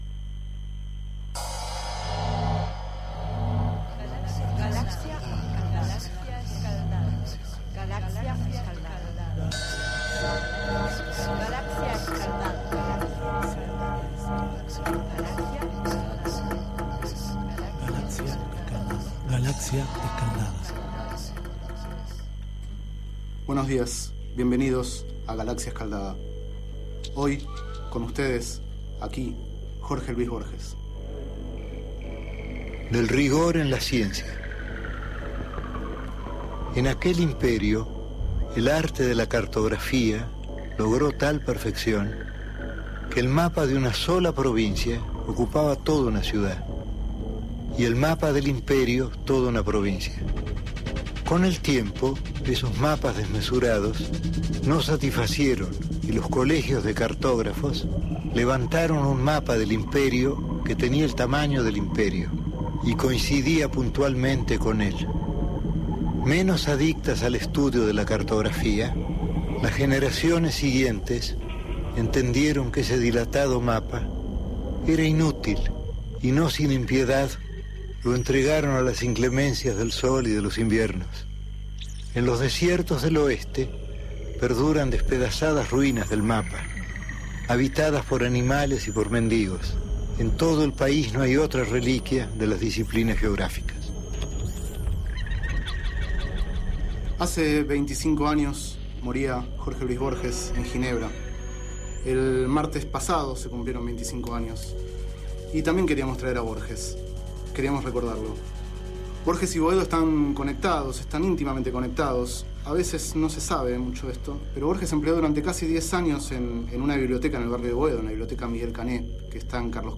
15º micro radial, realizado el 18 de junio de 2011, sobre Jorge Luis Borges.
Este es el 15º micro radial, emitido en el programa Enredados, de la Red de Cultura de Boedo, por FMBoedo, realizado el 18 de junio de 2011, sobre Jorge Luis Borges, a 25 años de su muerte.